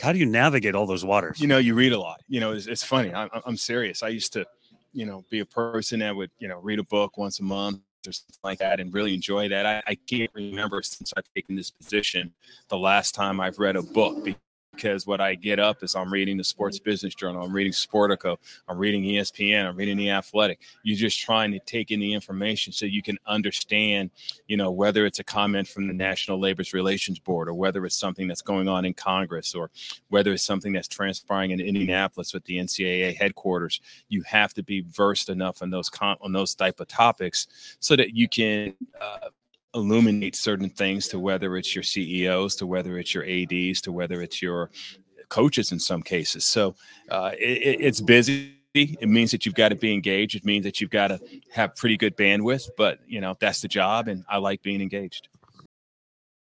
This conversation took place at the Valley men’s basketball media day.